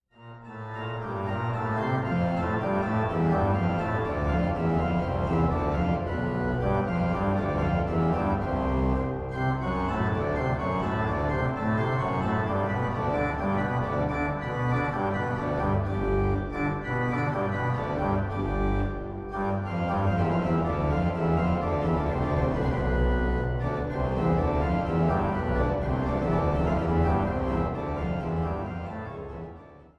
Schuke-Orgel der Kirche St. Divi Blasii Mühlhausen